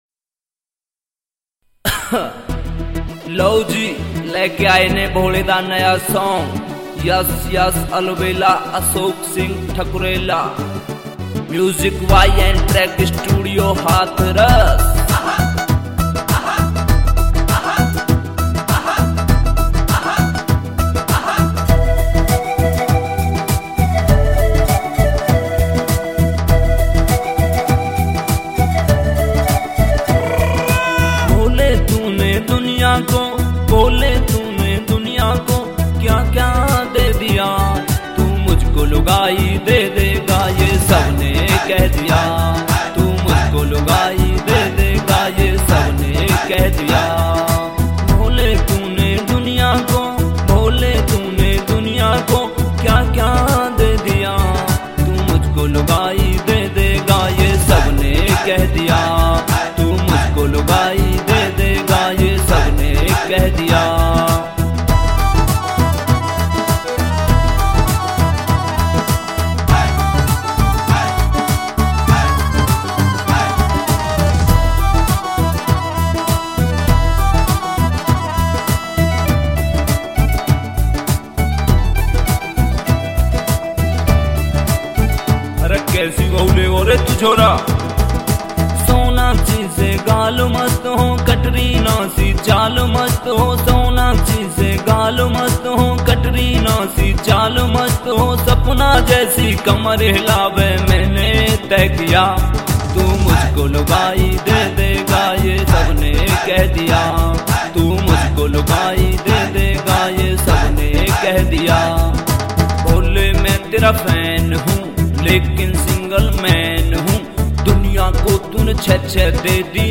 [ Bhakti Songs ]